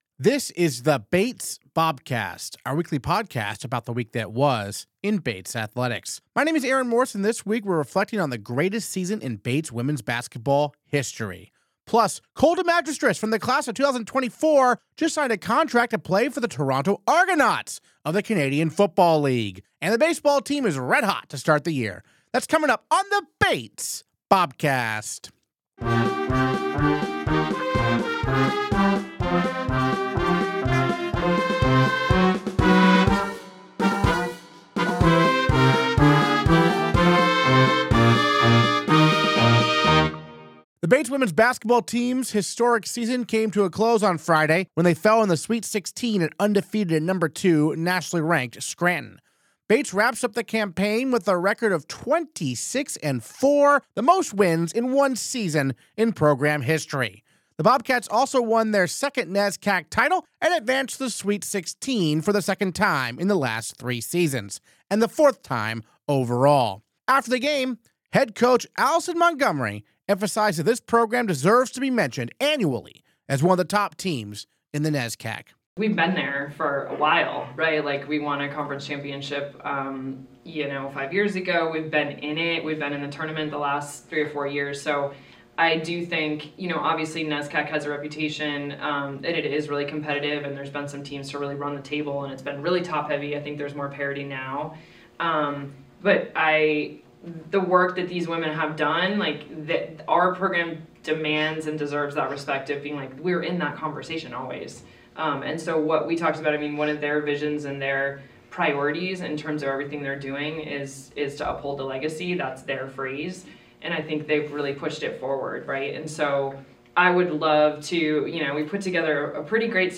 Interviews this episode